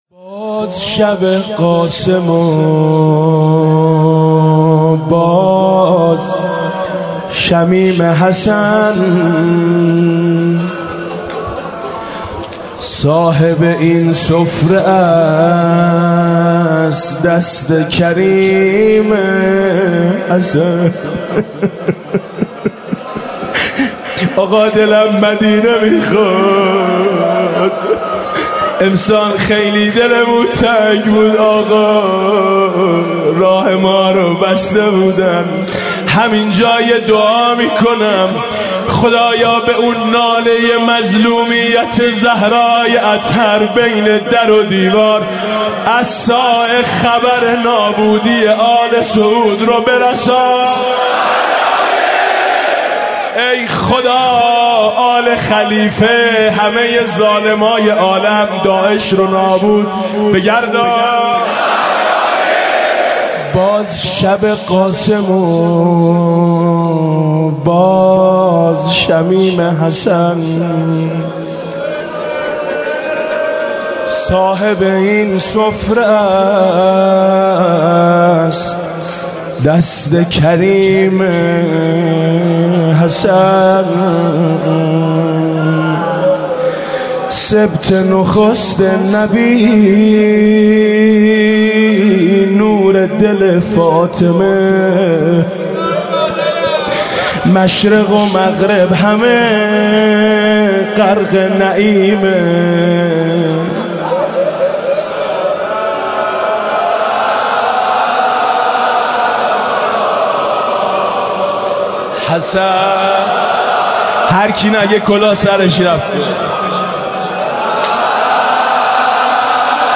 شب ششم محرم 95_روضه_صاحب این سفره است دست کریم حسن